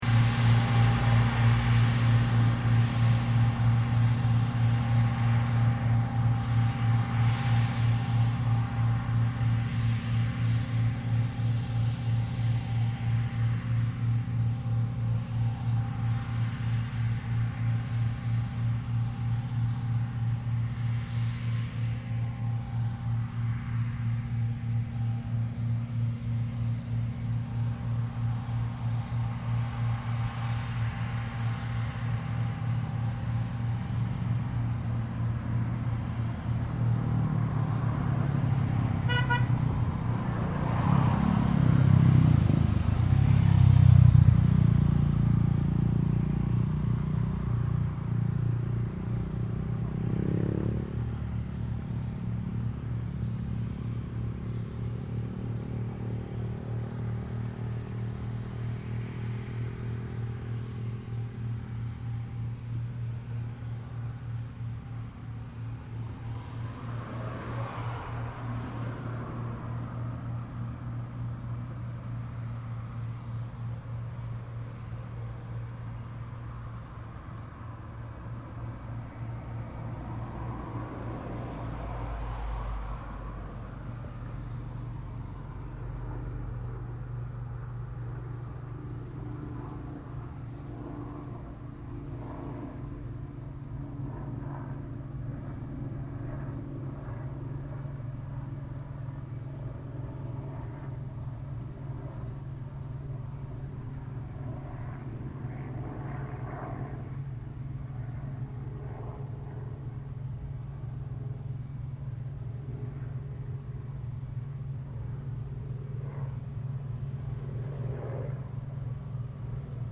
I took a few photos as the combine swung around to do another row and then got the recorder out.
The recording starts as the combine makes it’s way to the end of the field then returns up to the top where I was standing. You get to hear the traffic as it travels along the road behind me then as the combine returns, I turn around and capture the tractor and trailer coming back to collect the harvested grain.